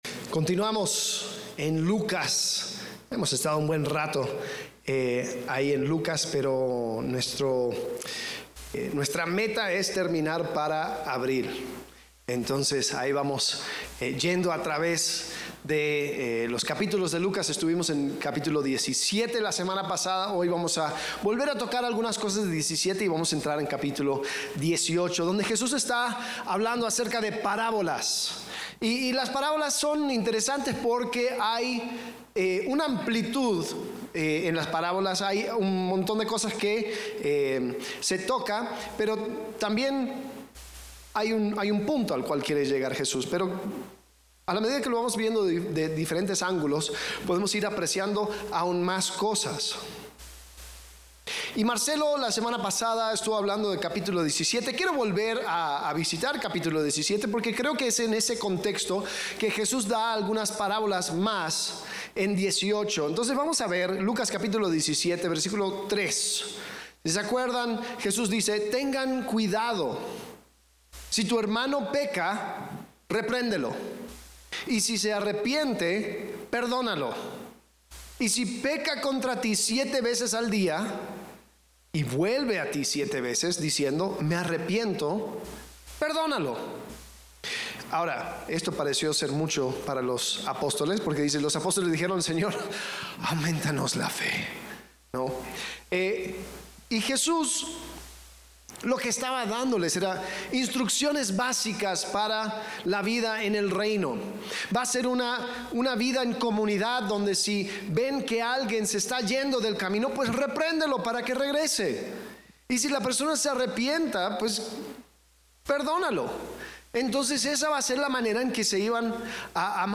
Servicio: Domingo